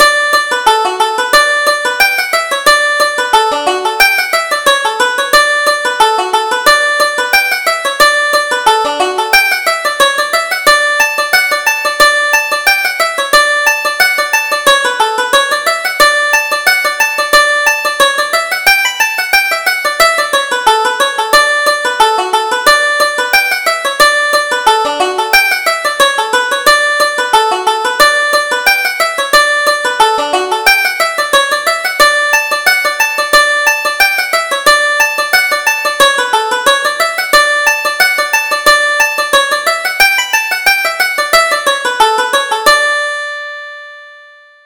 Reel: Mickey by the Fireside